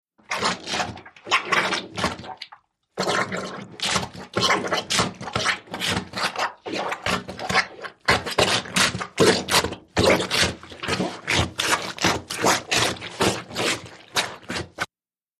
HOUSEHOLD SUCTION: INT: Rhythmic suction, comical plumbers helper on laundry.